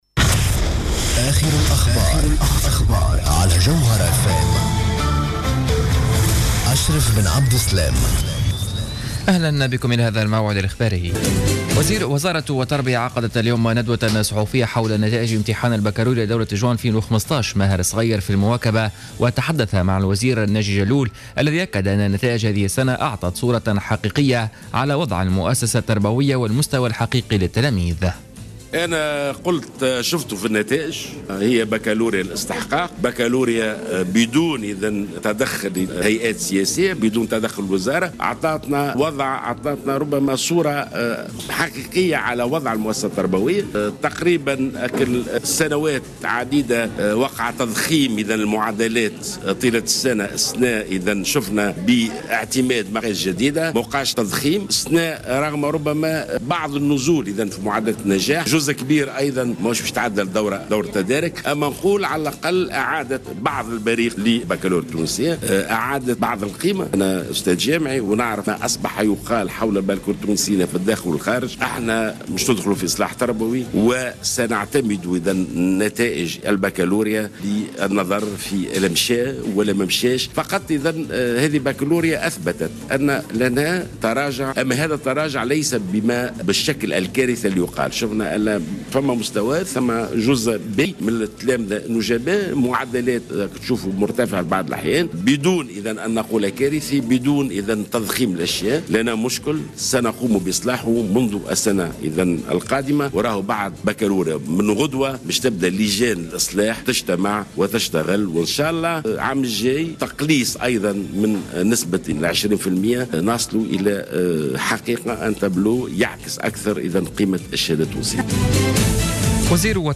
نشرة أخبار الخامسة مساء ليوم السبت 20 جوان 2015